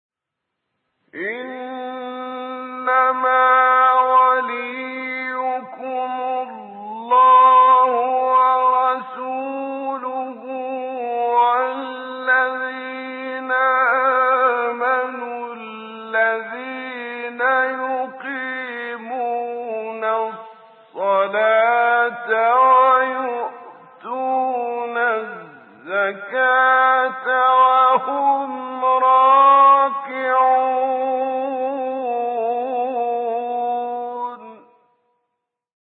تلاوت آیه ولایت با صوت احمد الرزیقی
برچسب ها: مقاطع صوتی از تلاوت ، آیه ولایت ، فرازی از تلاوت آیه ولایت ، تلاوت آیه ولایت از قاری مصری ، تلاوت آیه ولایت از قاری ایرانی